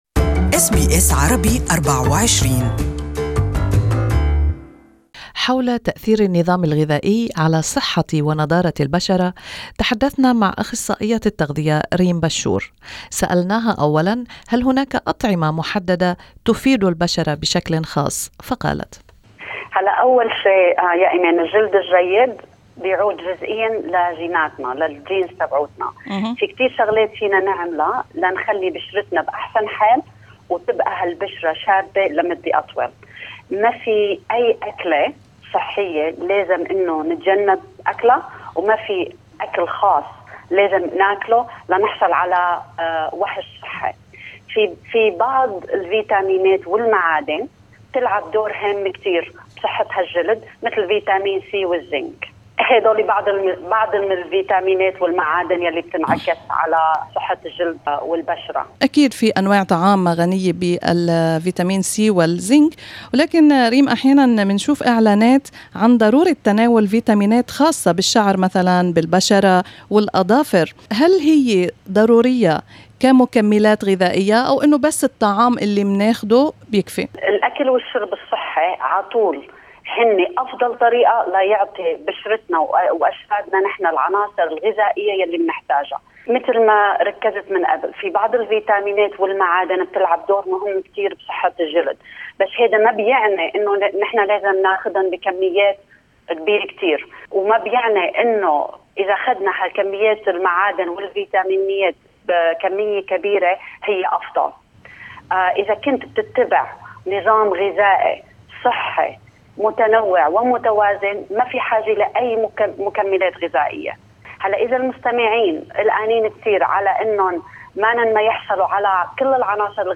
كما وتنصح بوقف التدخين وخفض المشروبات الغازية والكحولية. استمعوا إلى اللقاء كاملا تحت الشريك الصوتي أعلاه.